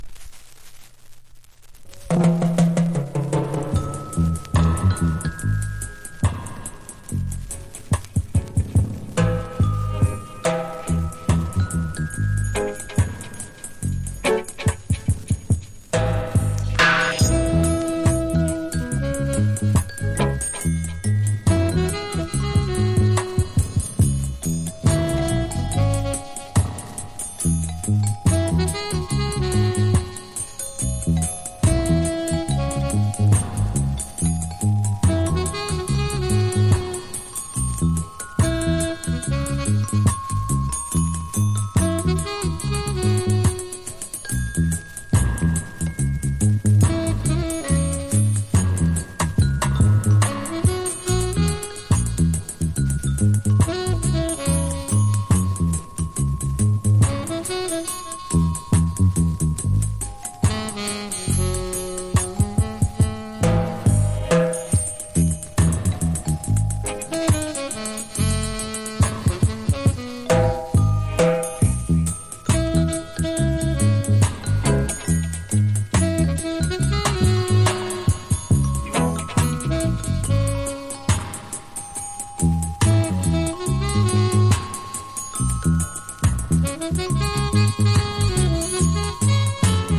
• REGGAE-SKA
DUB / UK DUB / NEW ROOTS